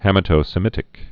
(hămĭ-tō-sə-mĭtĭk)